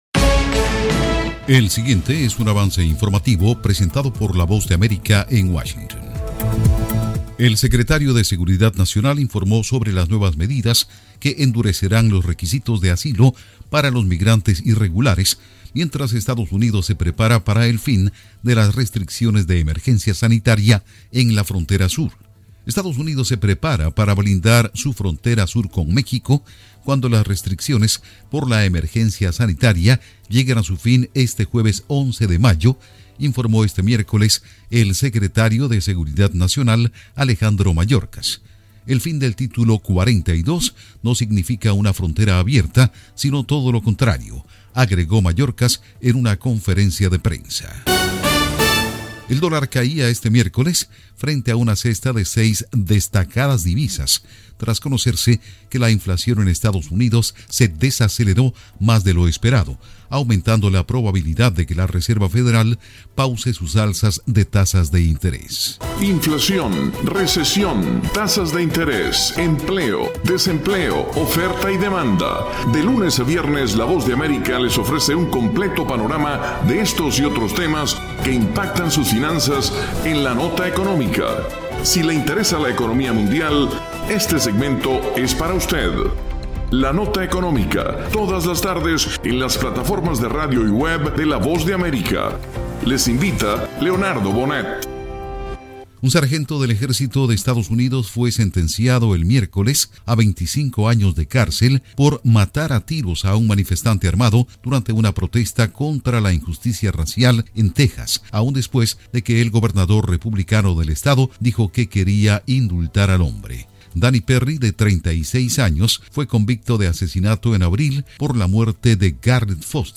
Avance Informativo 2:00 PM
El siguiente es un avance informativo presentado por la Voz de América en Washington.